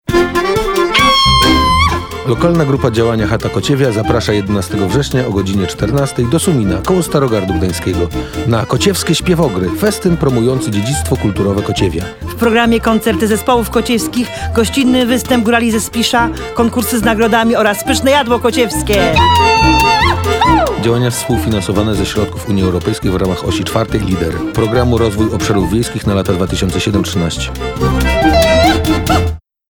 Pliki do pobrania Do pobrania jingel radiowy (0 kB)